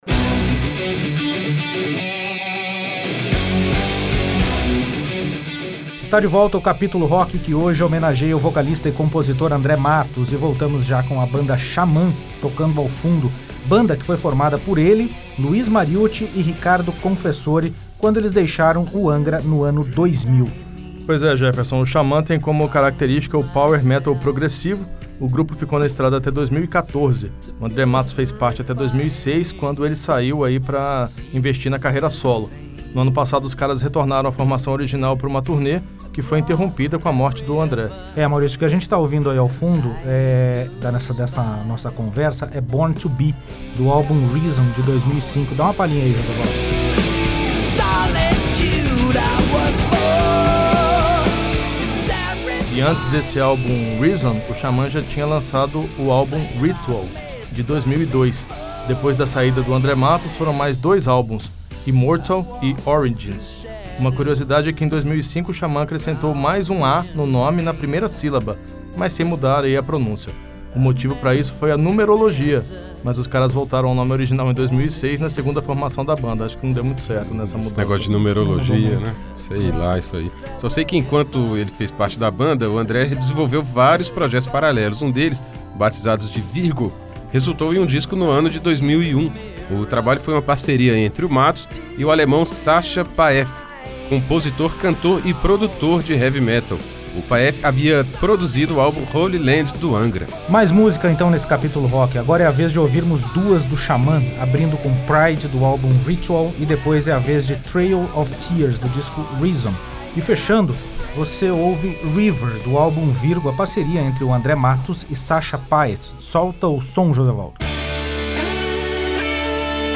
No Capítulo Rock vamos ouvir um pouco desse vasto trabalho deixado por uma das mais importantes vozes do heavy e do power metal.